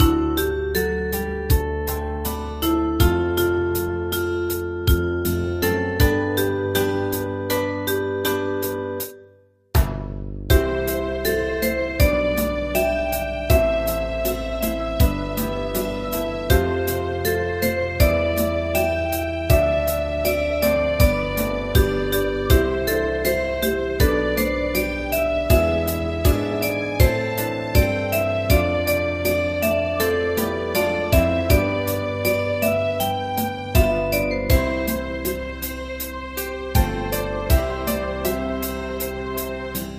大正琴の「楽譜、練習用の音」データのセットをダウンロードで『すぐに』お届け！
Ensemble musical score and practice for data.
Tags: Japanese , Japanese poplular .